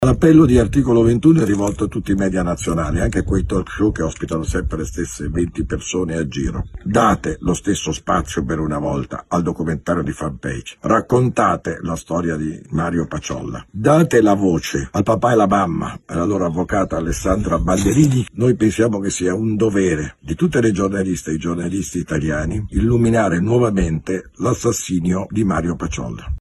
Ascoltiamo il portavoce Beppe Giulietti.